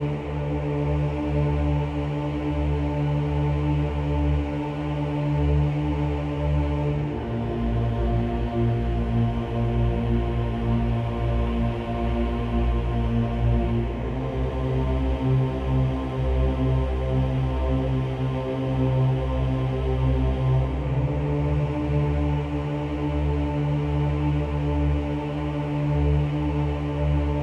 VTS1 25 Kit Melody & Synth